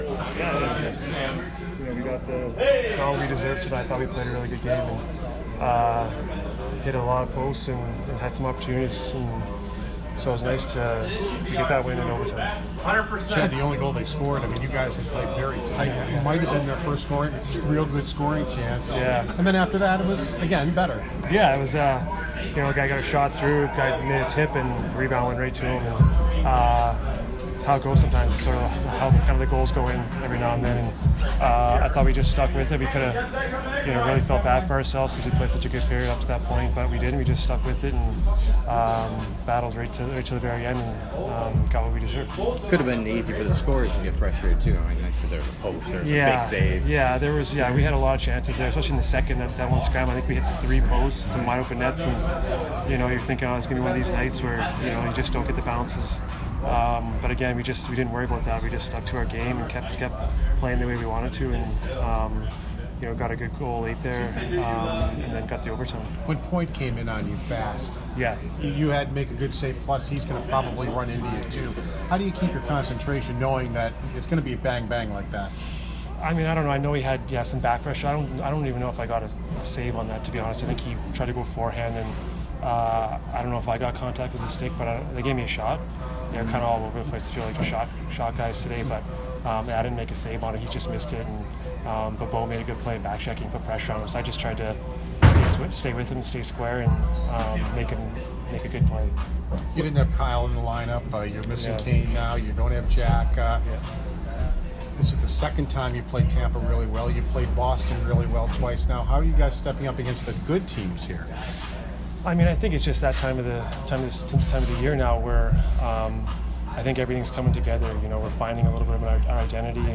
Chad Johnson post-game 2/28